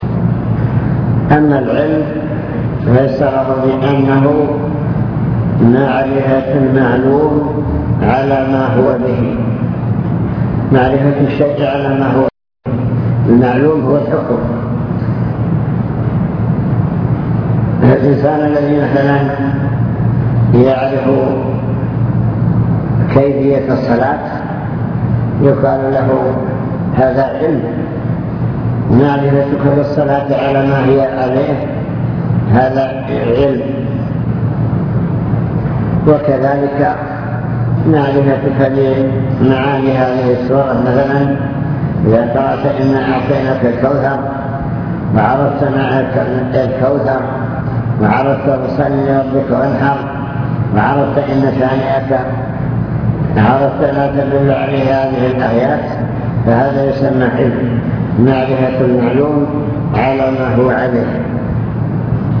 المكتبة الصوتية  تسجيلات - محاضرات ودروس  محاضرة في وادي ثرجوم